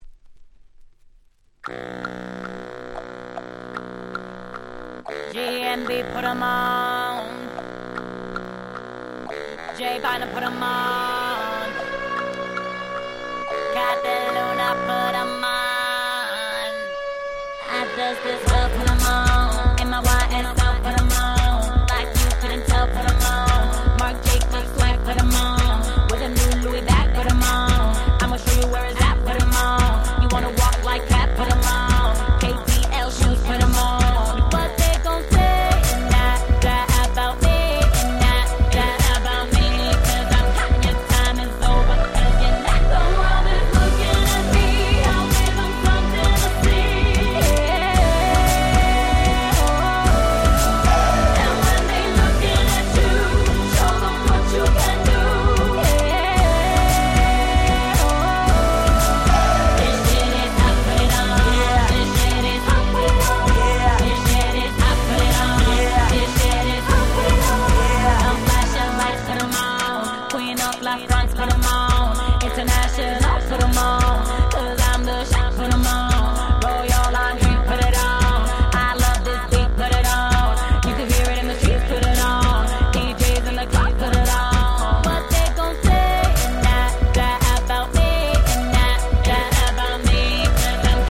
09' Smash Hit R&B !!